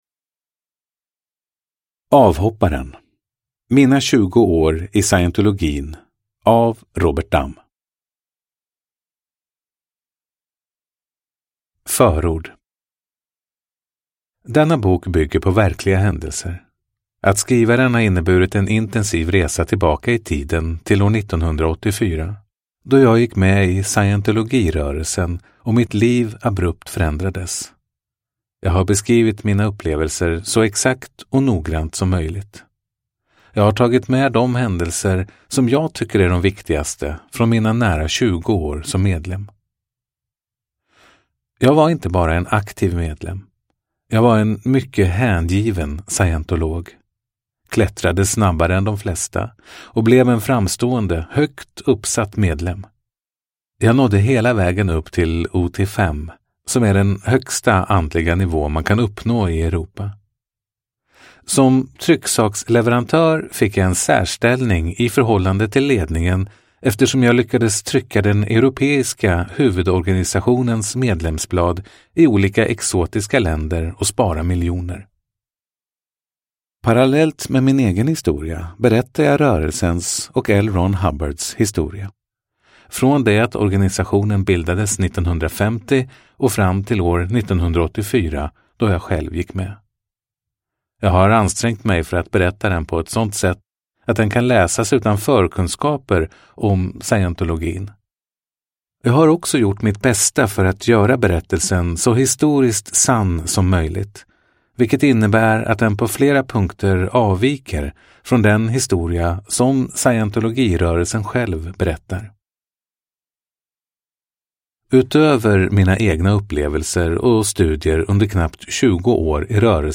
Avhopparen : mina 20 år i scientologin – Ljudbok – Laddas ner